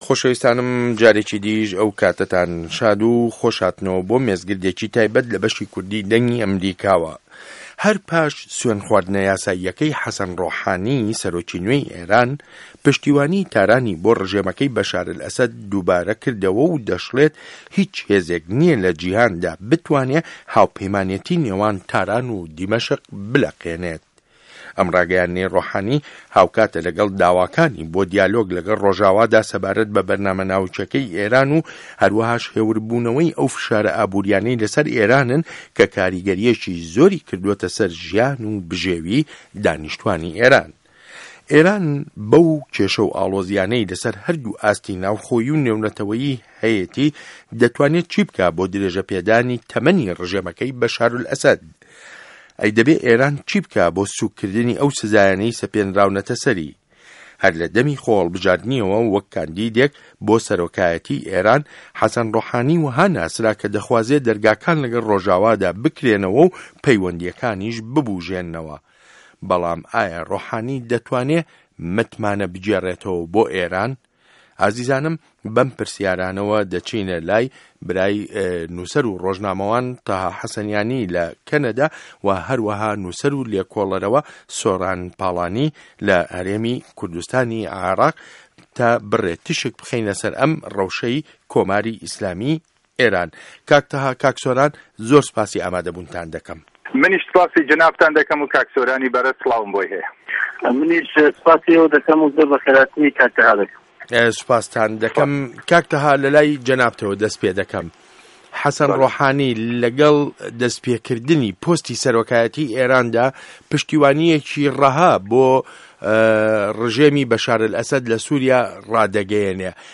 مێزگرد : ڕۆحانی، پشتیوانی بۆ ئه‌سه‌د و داوای دیالۆگ له‌ ڕۆژئاوا